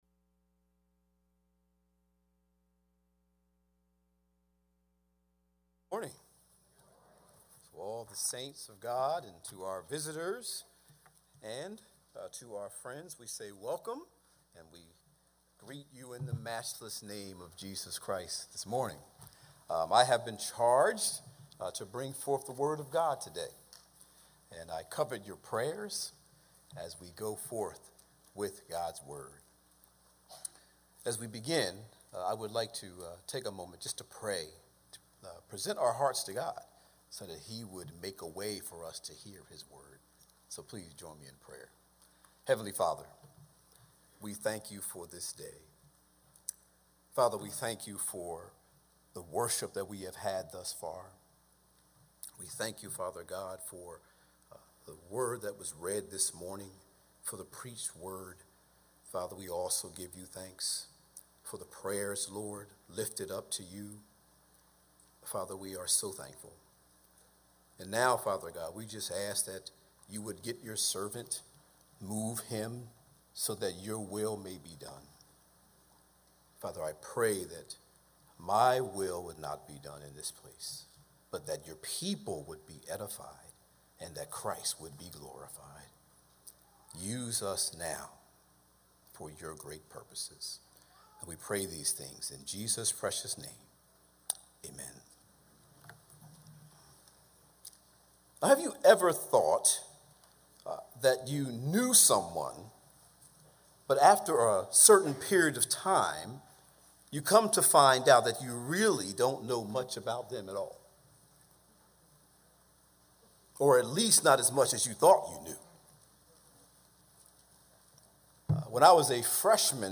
A message from the series "Behold our God!." Psalm 115 Introduction There are many who have suffered significant trauma in life.